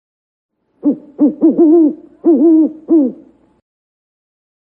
Owl Hoot
Owl Hoot is a free animals sound effect available for download in MP3 format.
040_owl_hoot.mp3